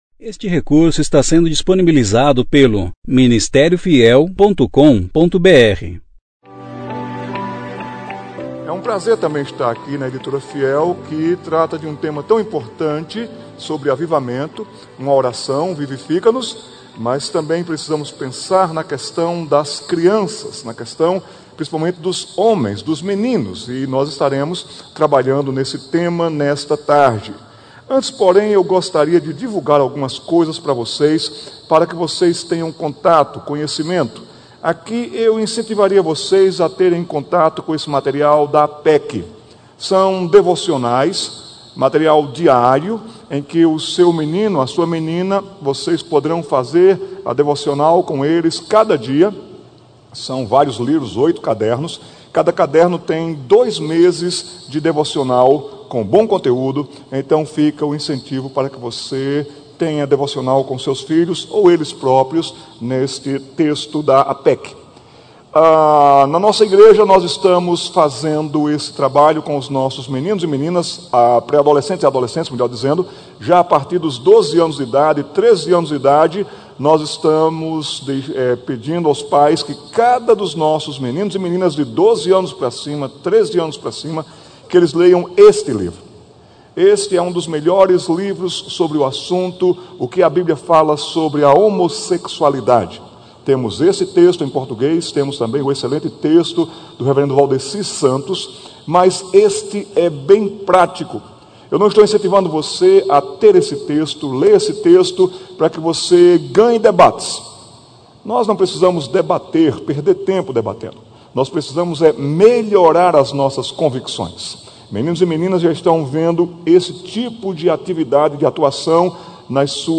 Conferência: 31ª Conferência Fiel para Pastores e Líderes - Brasil Tema: Vivifica-nos! E invocaremos